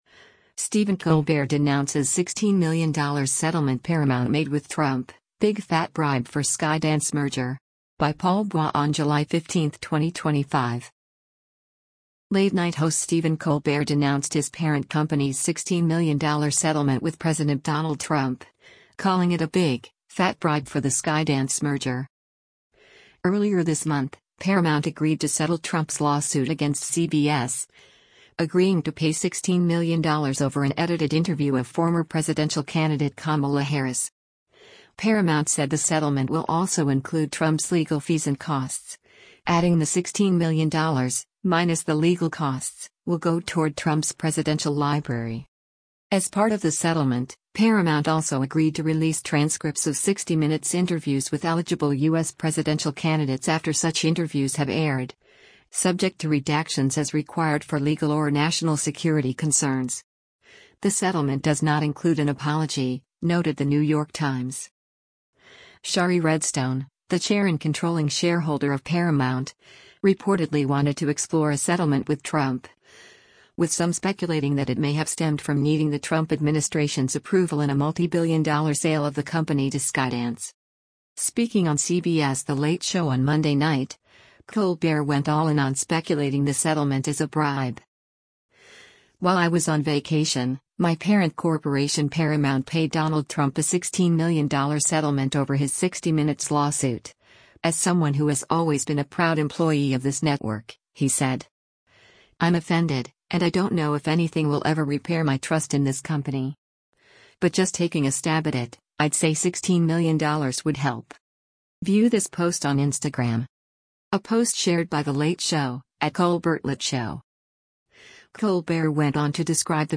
Speaking on CBS’ The Late Show on Monday night, Colbert went all in on speculating the settlement as a bribe.